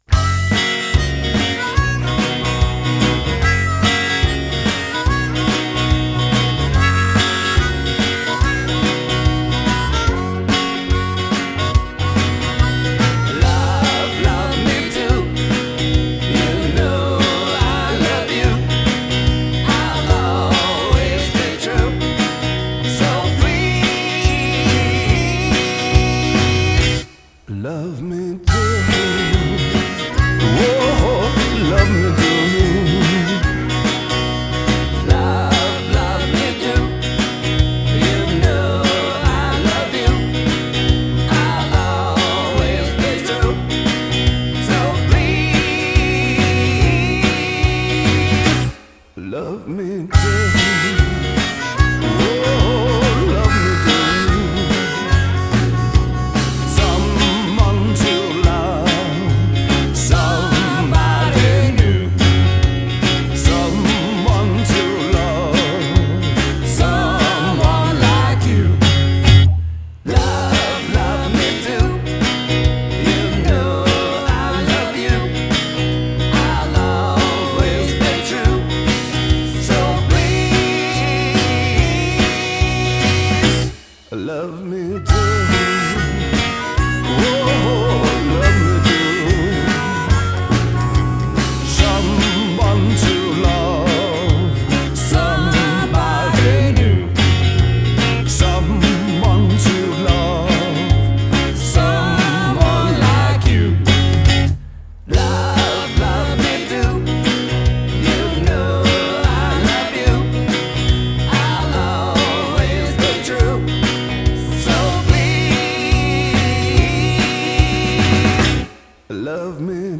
guitar + vocals + harmonica
bass + vocals
drums